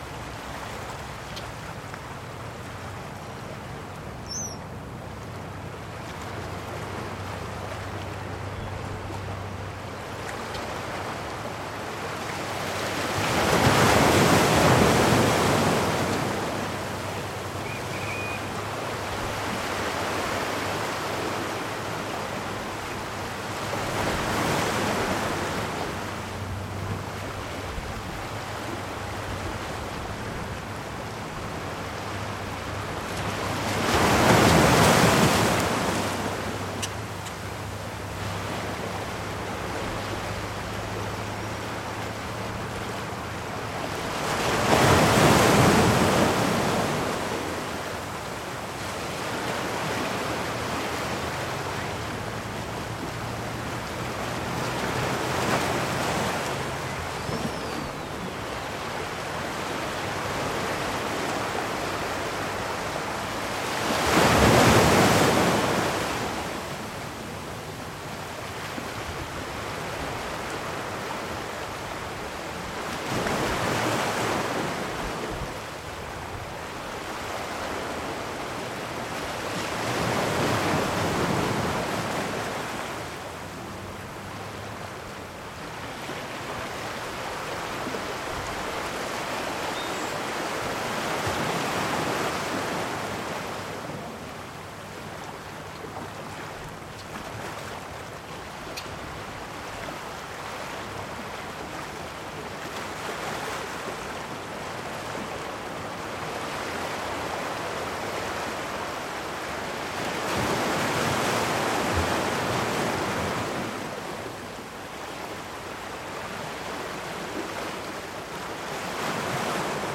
Monterey Bay sounds
pg_shore_05.mp3